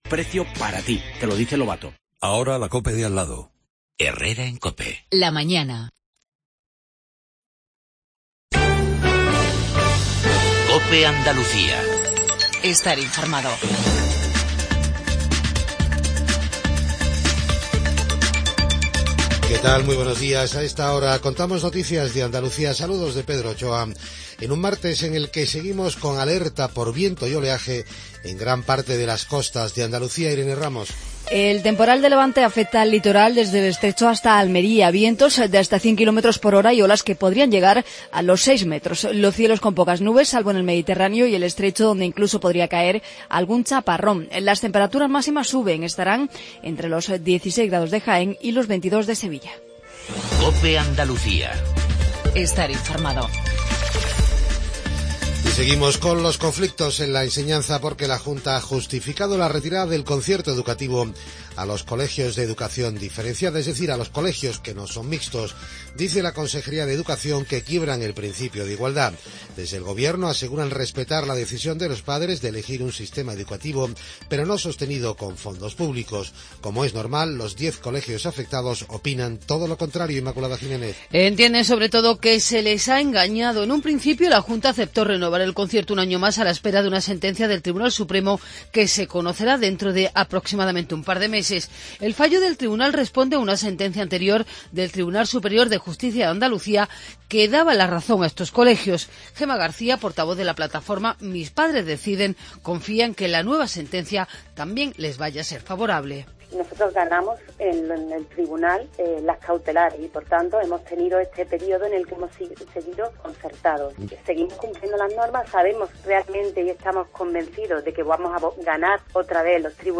INFORMATIVO REGIONAL MATINAL 7:20